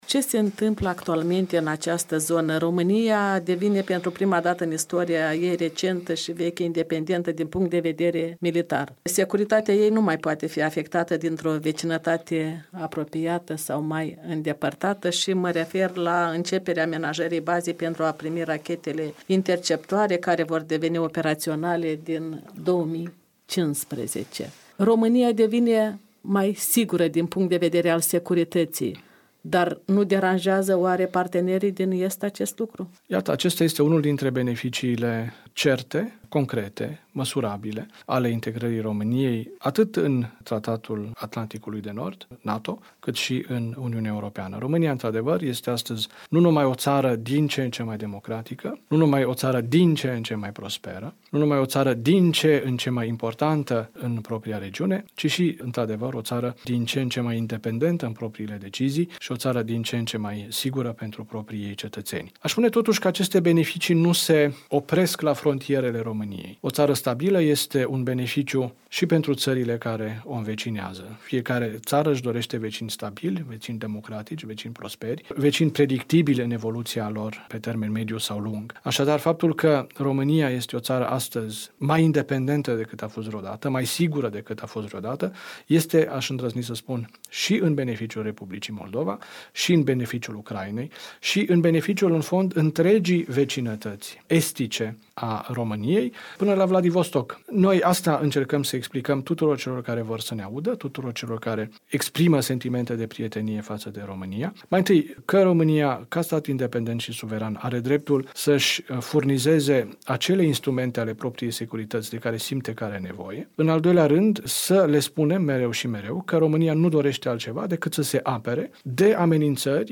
Interviuri la orele dimineții